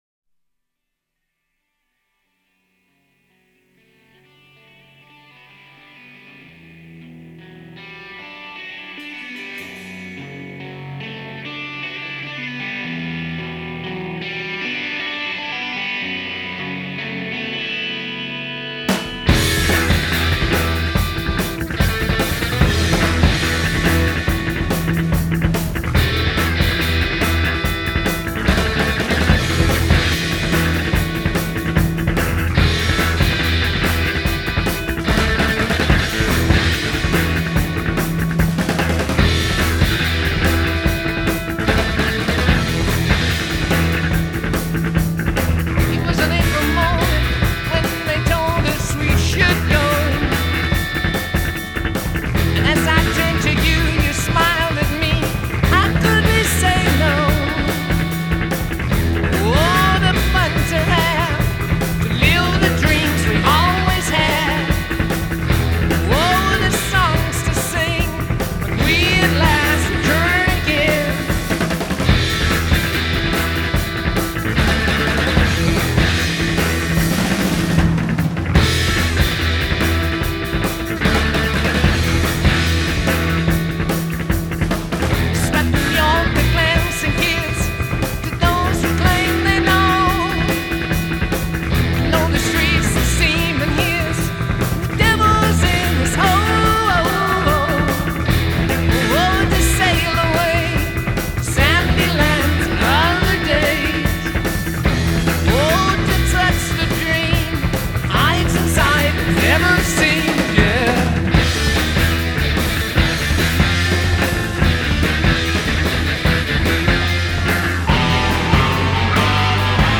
هارد راک